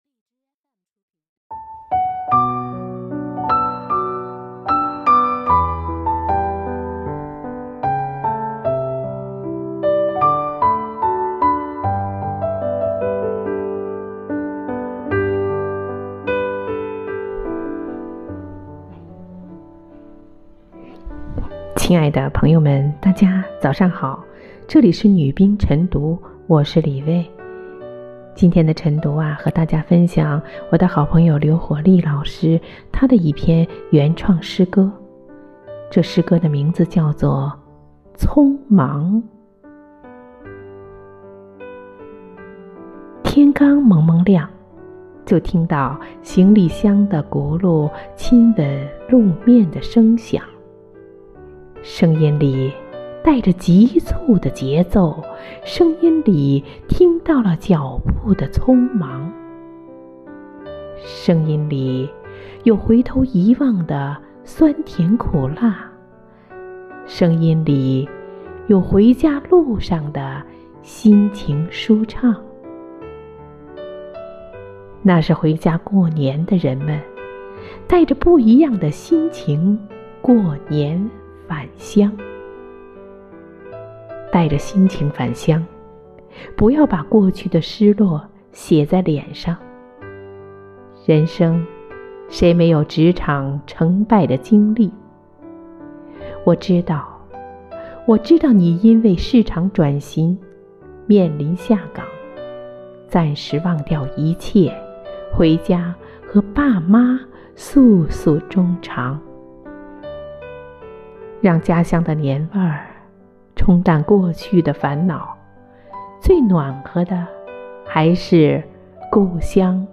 每日《女兵诵读》匆忙